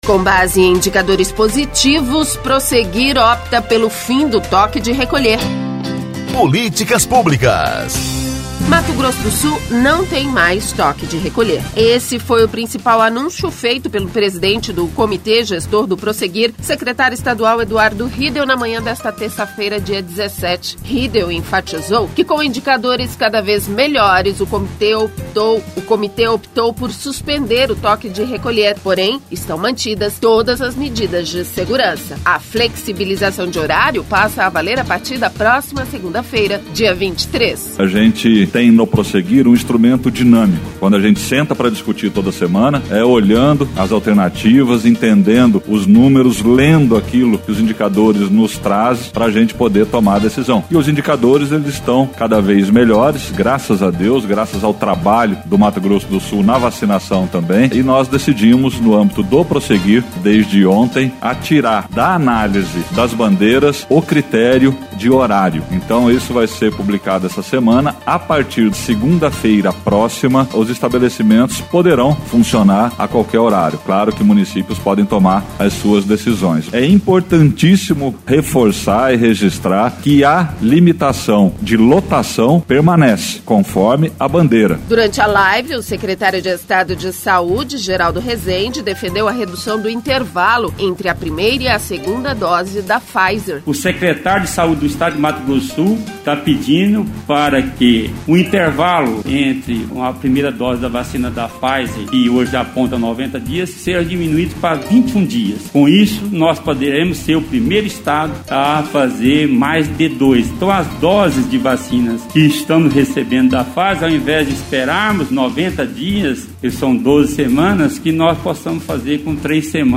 Esse foi o principal anúncio feito pelo presidente do Comitê Gestor do Prosseguir, Secretário Estadual Eduardo Riedel, na manhã desta terça-feira, dia 17.
A live com as decisões do Comitê Gestor do Prosseguir acontece todas as terças-feira com transmissão ao vivo pela página do facebook do Governo do Estado.